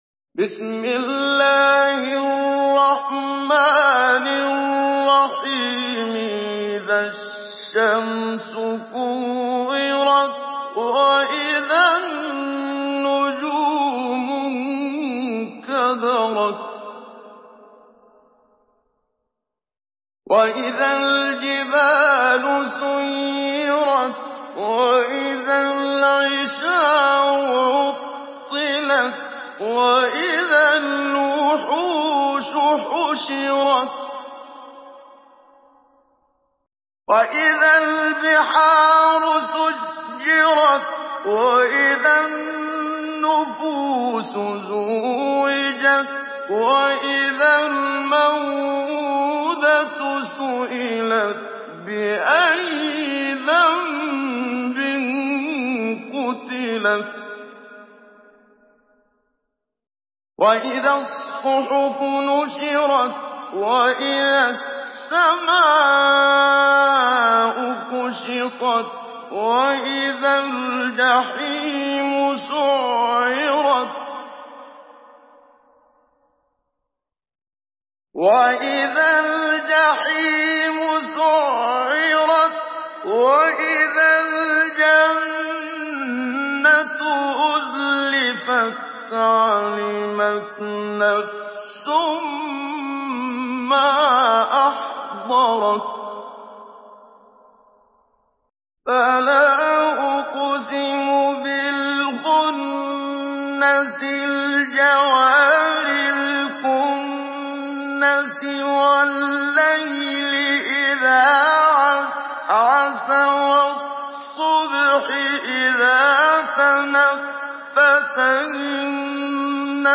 Surah আত-তাকভীর MP3 in the Voice of Muhammad Siddiq Minshawi Mujawwad in Hafs Narration
Surah আত-তাকভীর MP3 by Muhammad Siddiq Minshawi Mujawwad in Hafs An Asim narration.